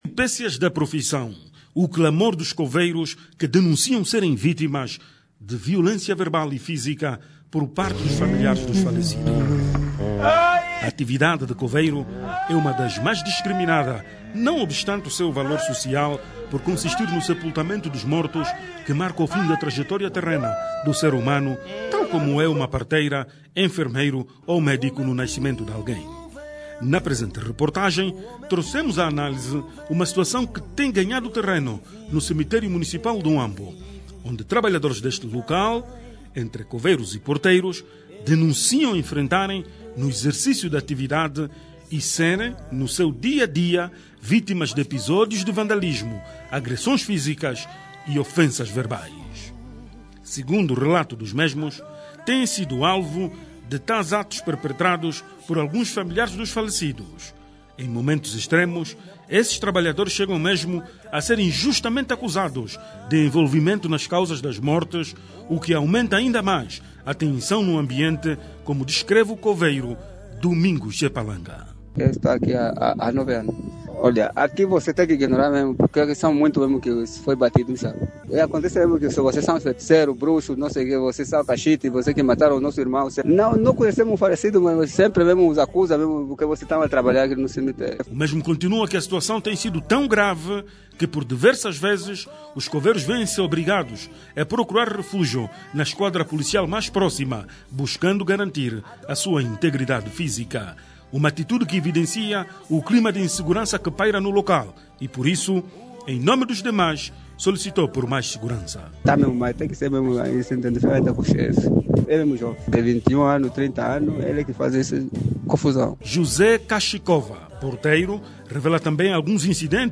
Entretanto, por cá, este profissional enfrenta “N” adversidades no exercício da sua actividade, que vão desde o estigma e as vezes até mesmo insultos. O caso da grande reportagem deste sábado, é uma amostra do que se passa por essa Angola adentro.
REPORTAGEM-DO-DIA13-HRS-BOM.mp3